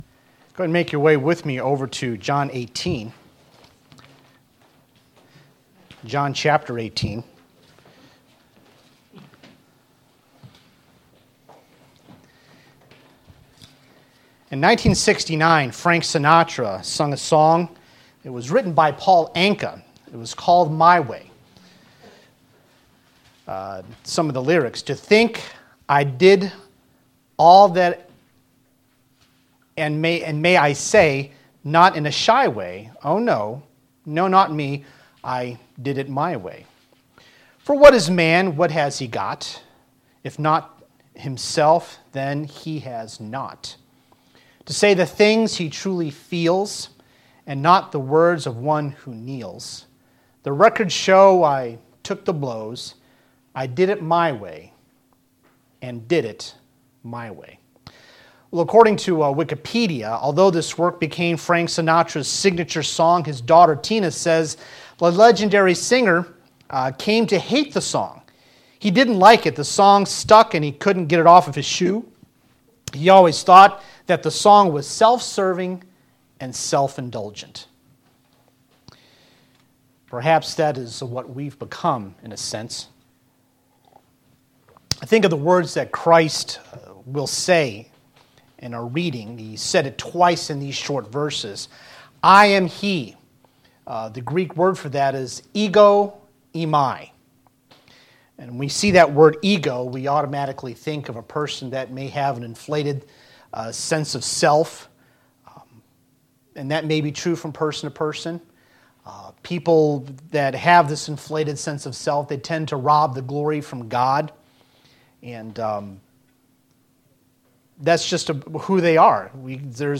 VBC-Sermon-10-7-18.mp3